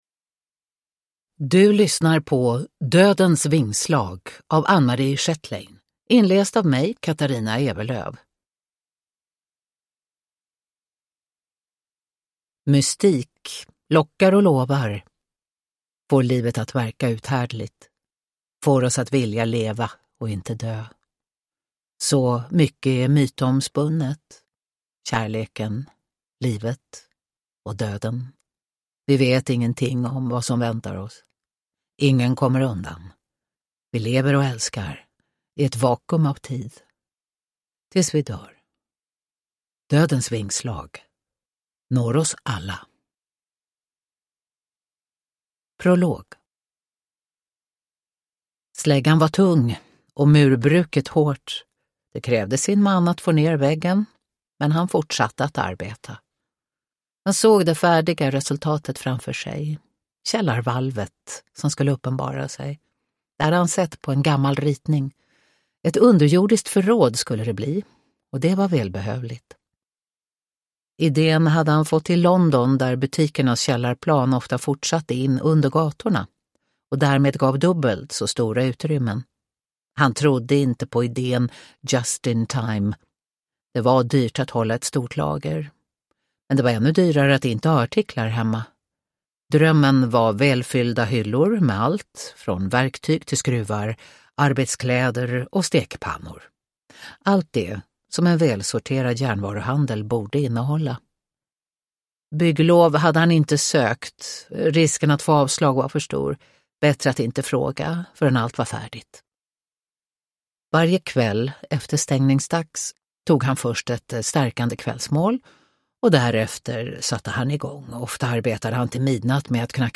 Dödens vingslag (ljudbok) av Anne-Marie Schjetlein